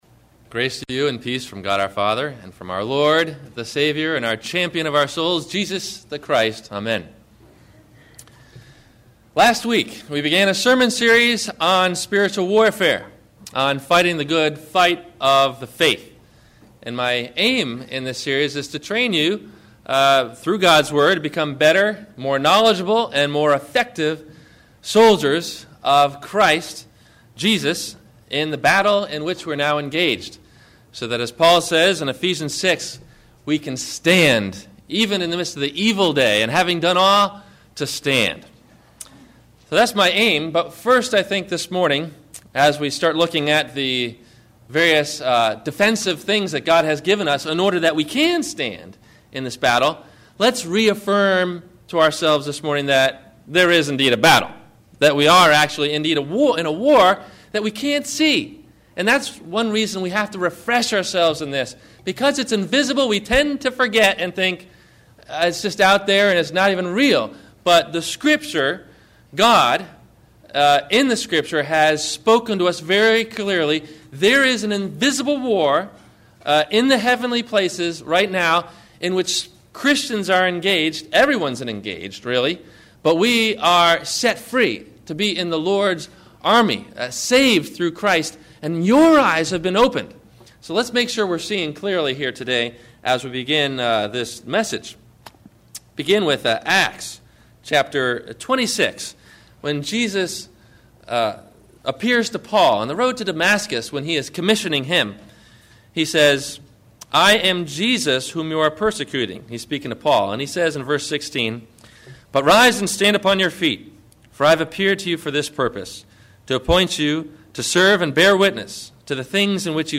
The Belt of Truth – Sermon – September 21 2008